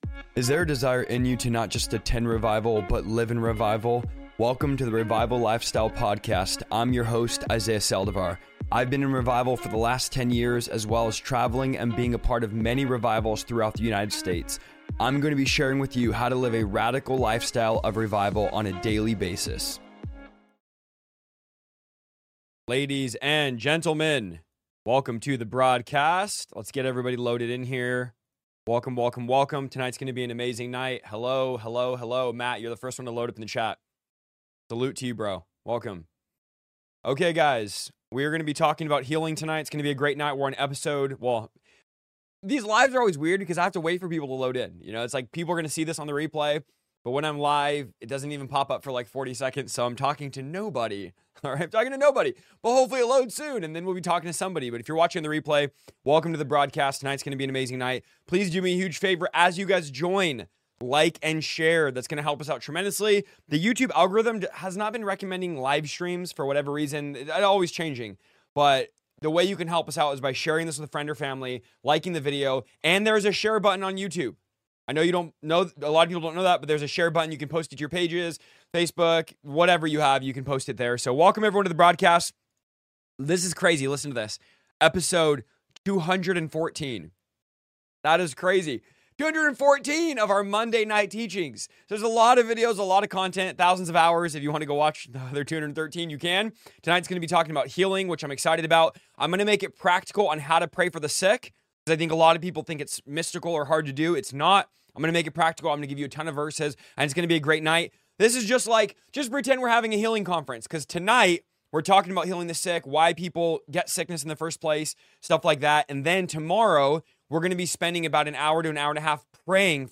In this livestream, I’ll teach you how to pray with boldness, biblical authority, and faith that activates healing.